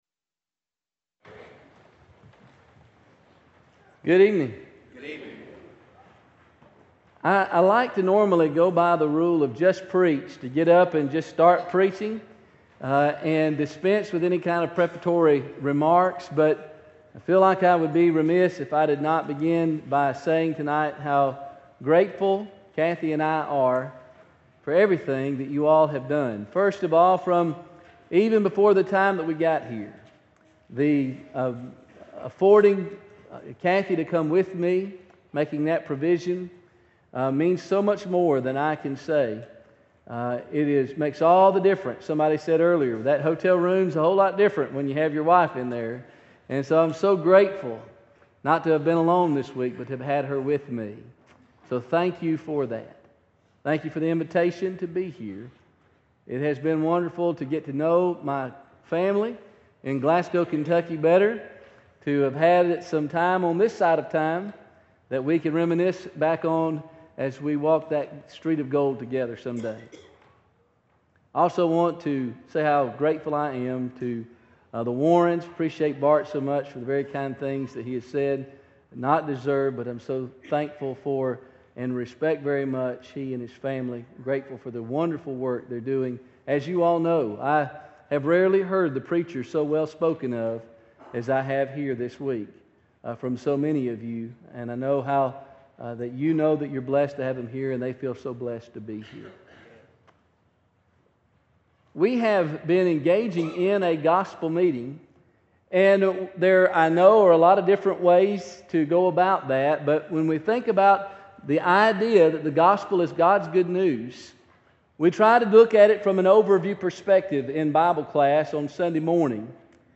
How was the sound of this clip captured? Gospel Meeting